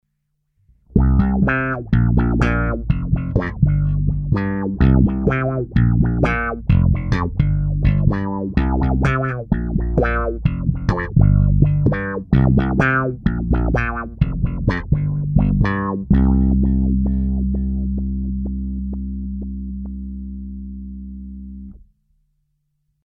Použita byla baskytara Ernie Ball Music Man StingRay 5, a jinak je to nahráno přes pedál rovnou do zvukovky a jen normalizováno.
Hra prsty